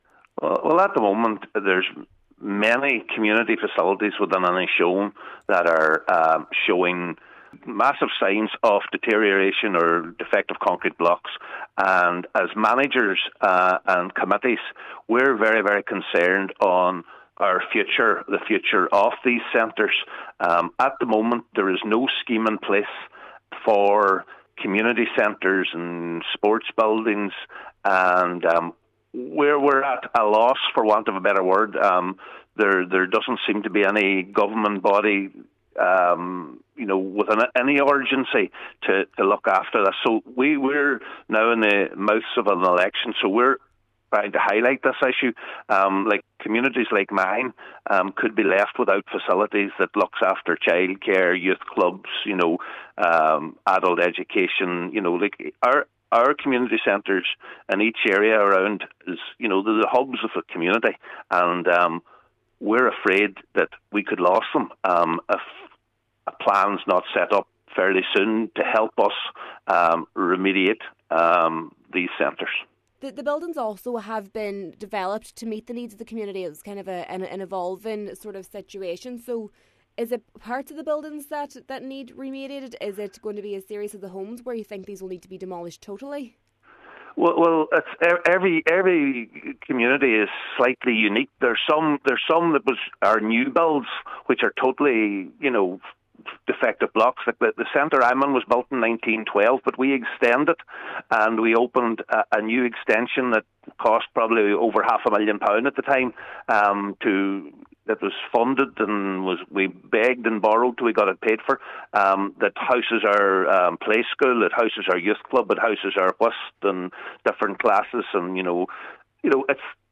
Manager of Malin Community Centre, Cllr Ali Farren says with an election on the horizon, candidates need to be willing to get a scheme in place if elected: